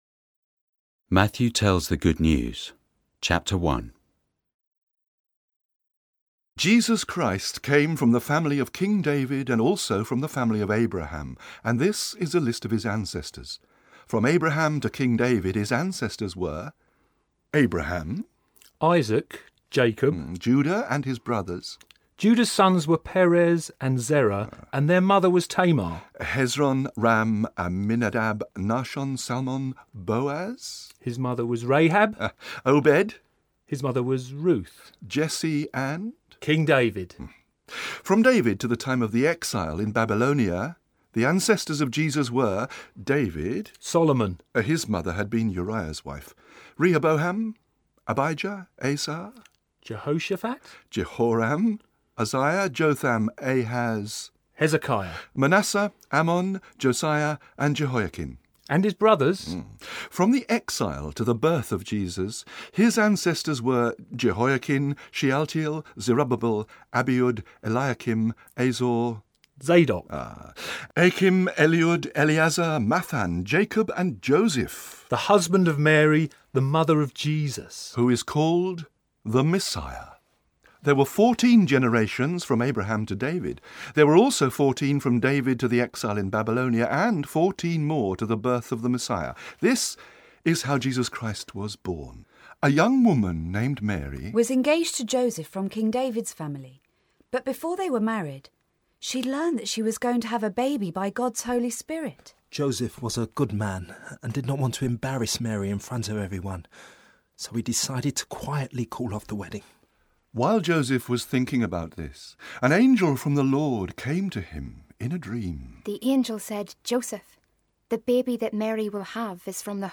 Here is part one of a version read by the Riding Lights Theatre Company, produced by the Bible Society.